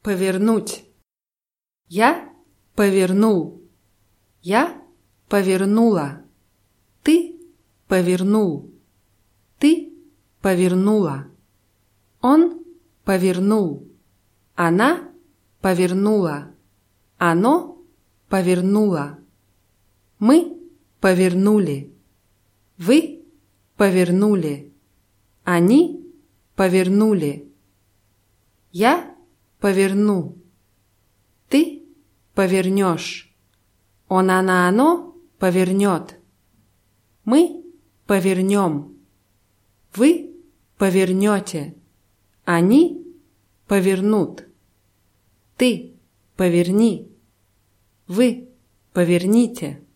повернуть [pawʲirnútʲ]